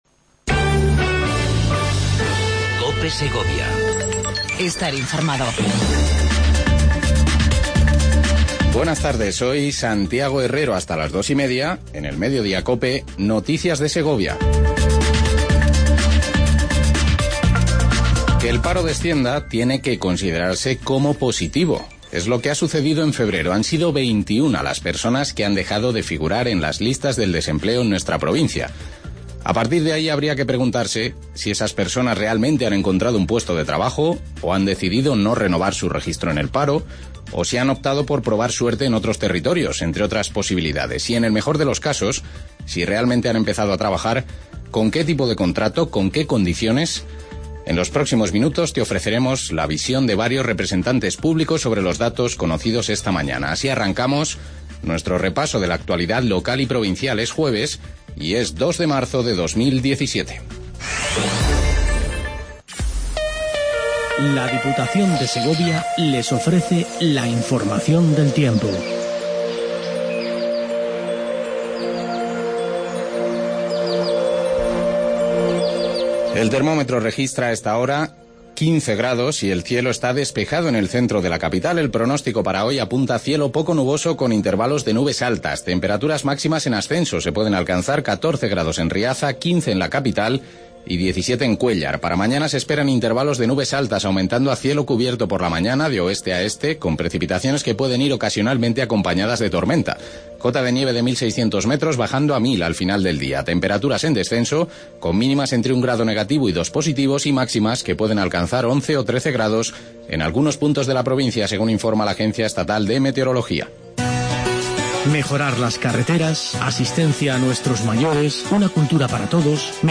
INFORMATIVO MEDIODIA COPE EN SEGOVIA